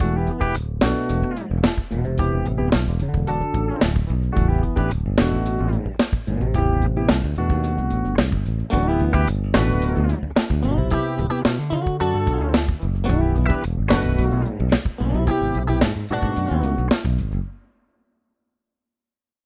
יש ליין של גיטרה בס, תופים וגיטרה חשמלית אני רוצה לדעתדבר אחד לא הוספתי שום אפקטים ולא נגעתי בצלילים האם זה נשמע ריאליסטי (כלומר כאילו הוקלט בכלים חיים באולפן)?